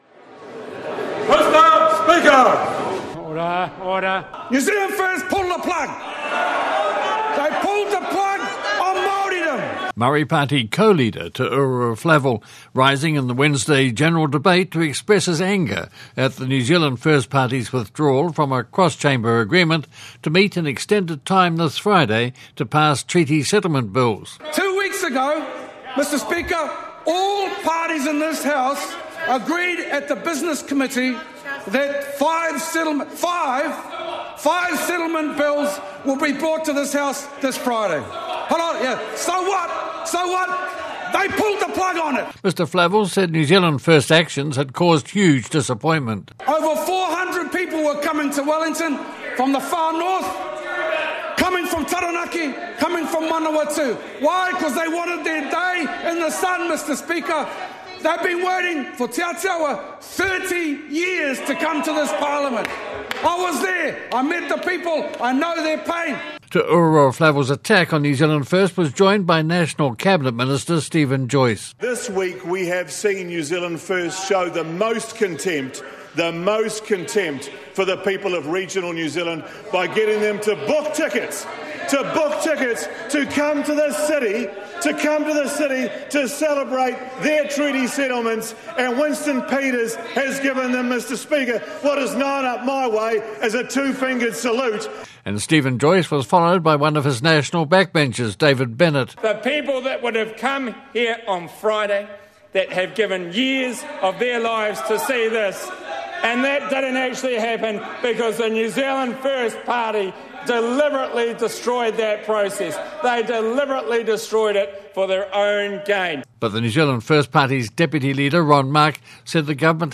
Maori Party co-leader, Te Ururoa Flavell, slams New Zealand First for breaking cross-party agreement to sit this Friday to pass five Treaty settlement Bills.